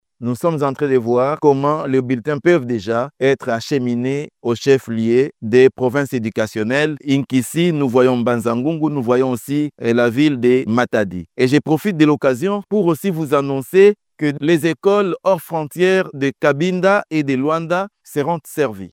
Une autre déclaration de l'inspecteur général à l'Education nationale: